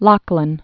(läklən)